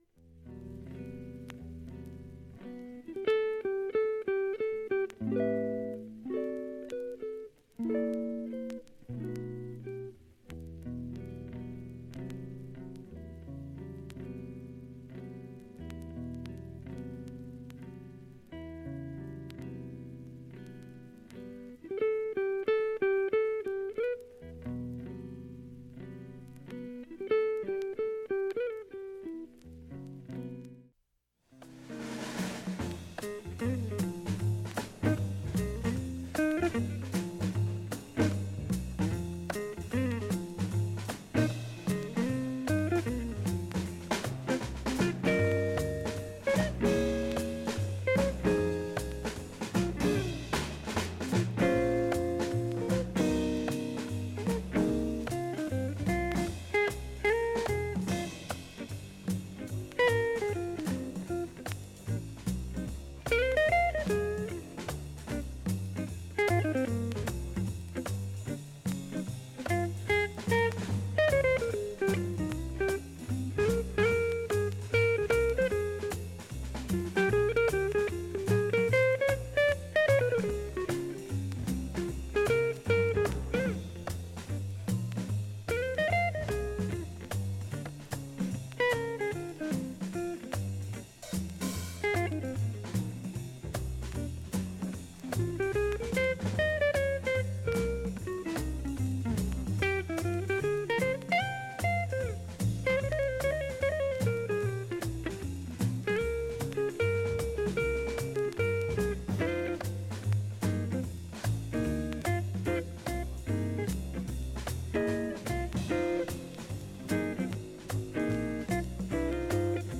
盤面きれいで、静かな部もチリツキも無く、
音質良好全曲試聴済み。
わずかなプツが１２回出ます。